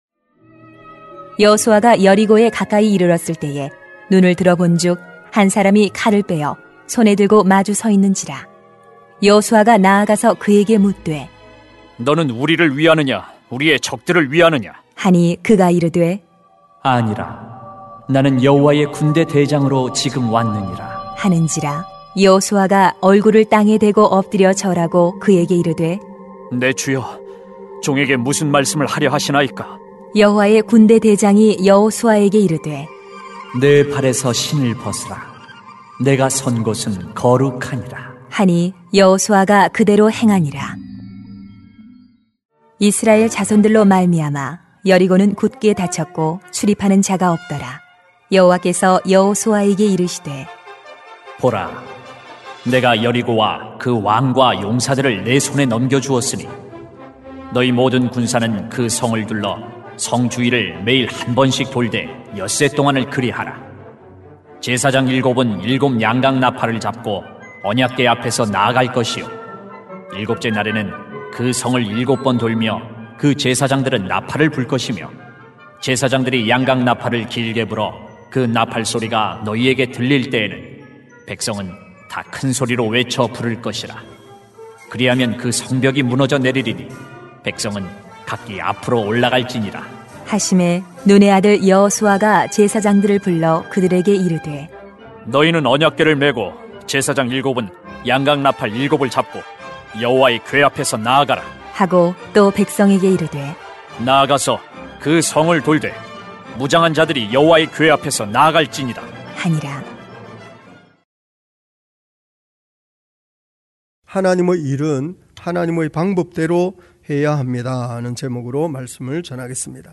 [수 5:13-6:7] 하나님의 일은 하나님의 방법대로 해야합니다 > 새벽기도회 | 전주제자교회